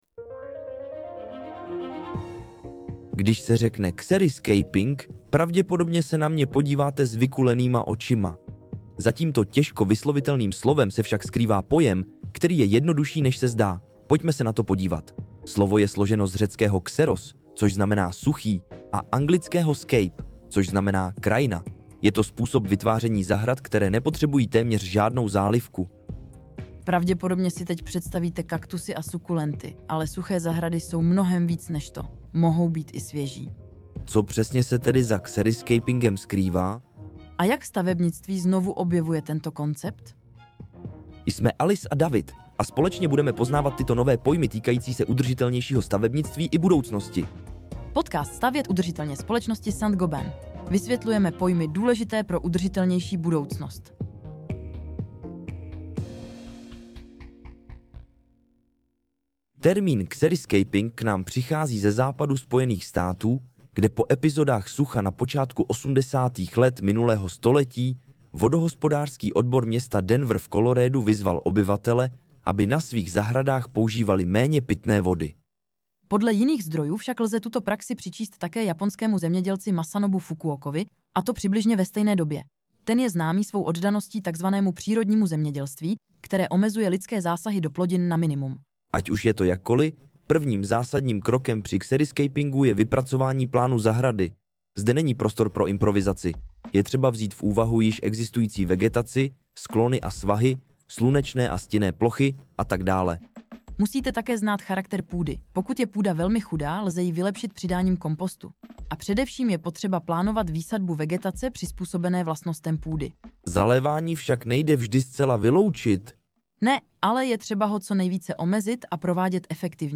V této epizodě rozluštíme, co přesně toto zvláštní slovo znamená… 🌱 Tento podcast pro vás z francouzského originálu přeložila a také namluvila umělá inteligence, aby i ten byl ekologicky šetrný a pomáhal nám snižovat uhlíkovou stopu.